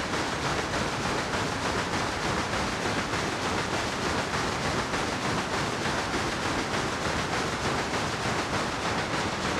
STK_MovingNoiseB-100_01.wav